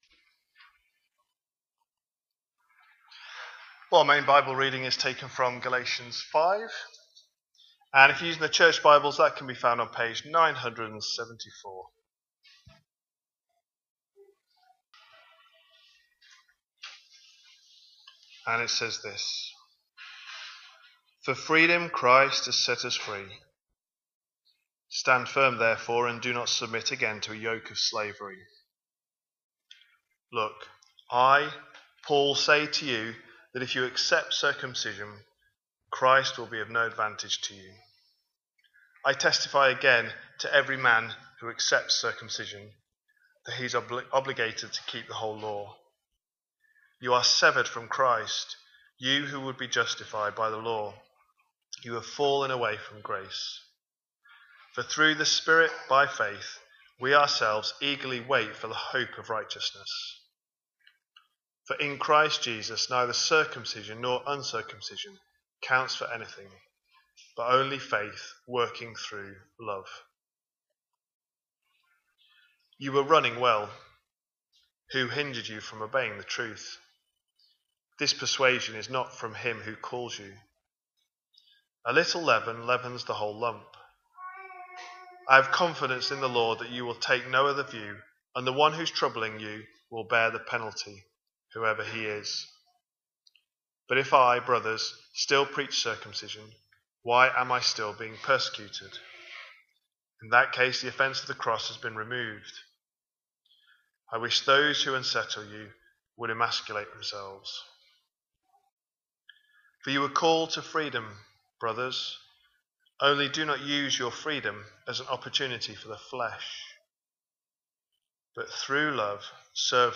A sermon preached on 29th June, 2025, as part of our Galatians series.